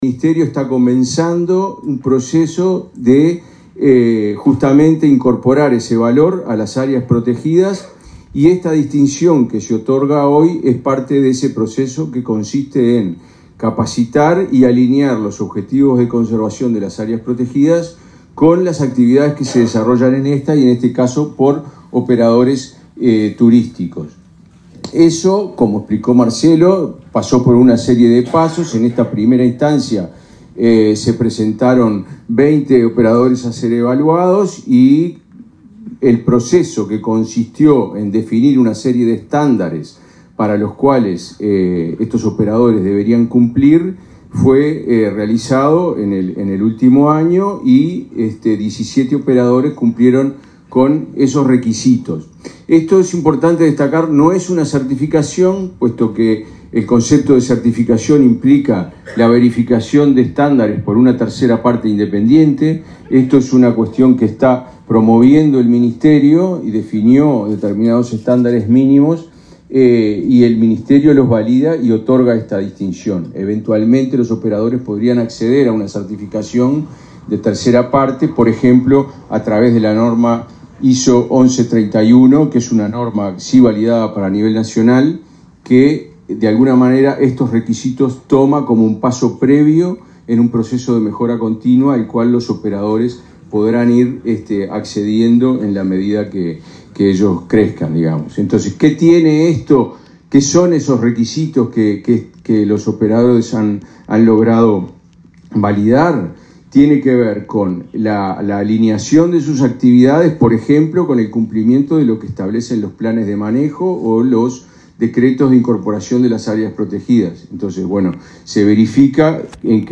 Palabras del director nacional de Biodiversidad y Servicios Ecosistémicos
El director nacional de Biodiversidad y Servicios Ecosistémicos, Gerardo Evia, participó este viernes 21 en Maldonado en la entrega de distinciones a